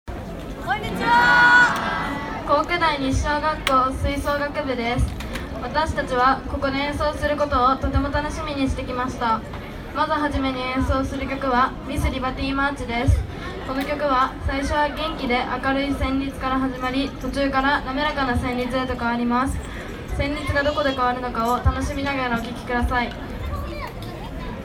９月２４日（日），湖北駅前で開催された「ほくほくサンバ」に吹奏楽部が出演し，「MissLIBERTY」「DISNEY’S MAGIC KINGDOM」「SING SING SING」「君の瞳に恋して」「王様と動物たちの散歩」の５曲を地域の方々の前で堂々と演奏しました。４年生の踊りも交え、楽しい雰囲気で会場を盛り上げました。